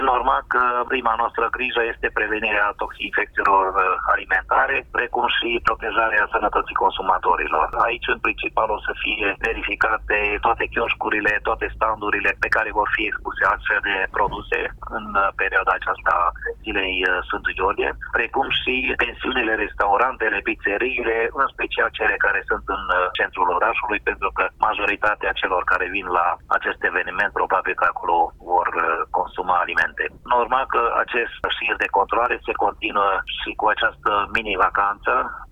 Directorul Direcției Sanitar-Veterinare și pentru Siguranța Alimentelor Covasna, Siko Barabasi Sandor: